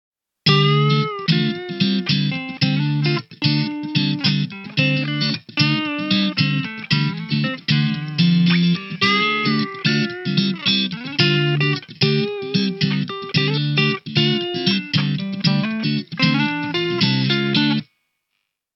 In unserem Fall habe ich Funk-typisch ebenfalls auf einen Cleansound, jedoch mit wesentlich präsenteren Mitten gesetzt, damit sich das Solo gut gegen die Rhythmusgitarre durchsetzt.
Funky Bluesgitarre mit Apollo Twin
Sowohl die Rhythmus- als auch die Sologitarre platziere ich in einem mit Korkwänden und Sitzreihen ausgestatteten, mittelgroßen Jazzclub.
8_ohne_Hall.mp3